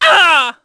Gladi-Vox_Damage_01.wav